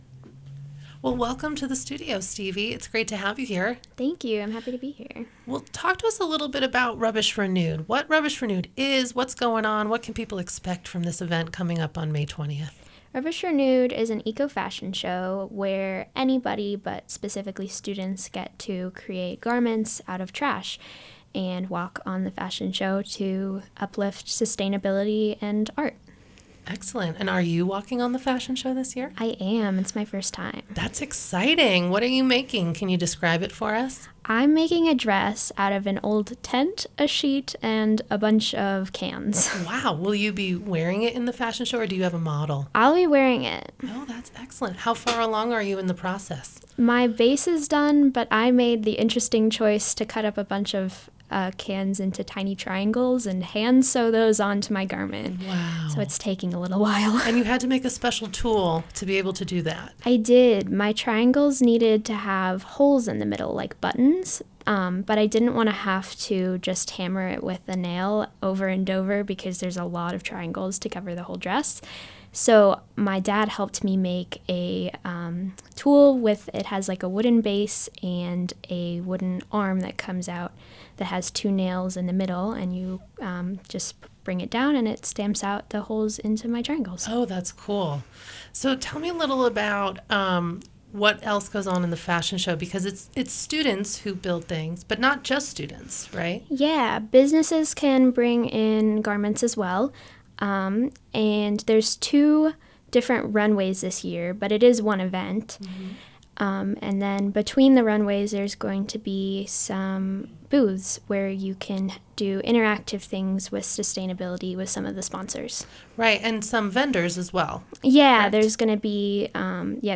101.7 Interview